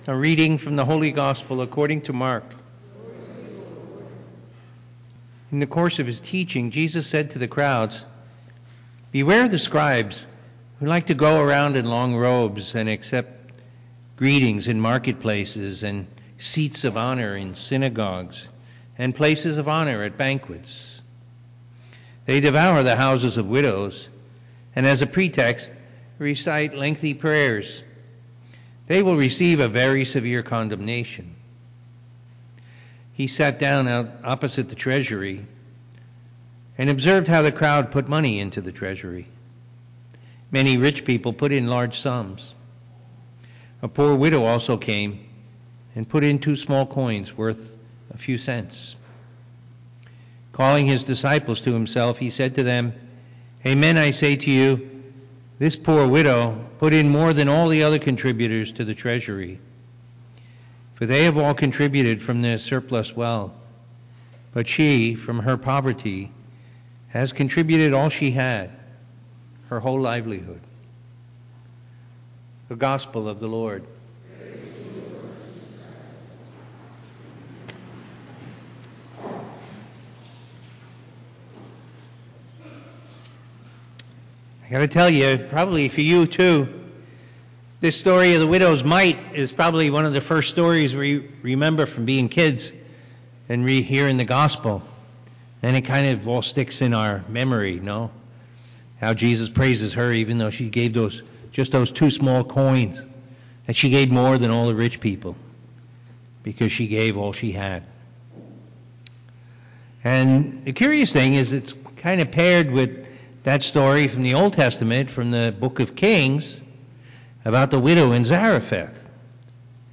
Looking to go deeper in your prayer life? Listen to the homily from the Sunday Mass and meditate on the Word of God.